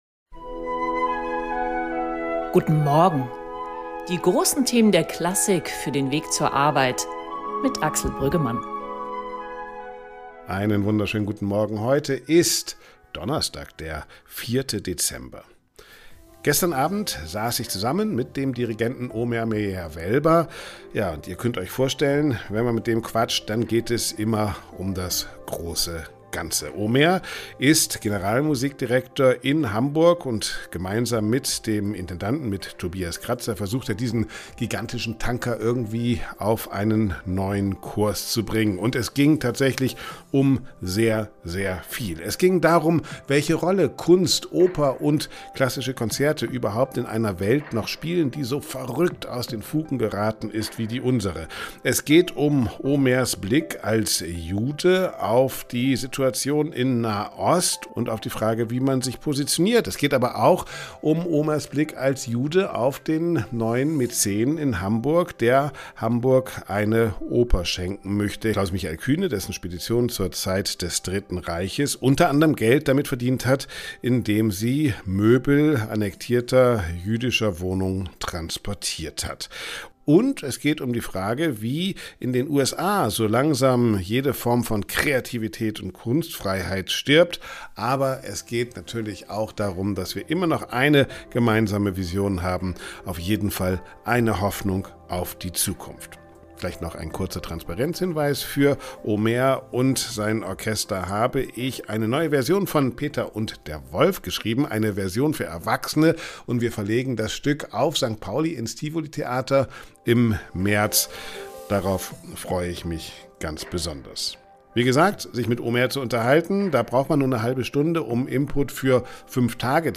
Der Generalmusikdirektor der Hamburgischen Staatsoper, Omer Meir Wellber, legt im Podcast-Gespräch mit Backstage Classical seine Vision für die Kunst in einer sich radikal verändernden Welt dar.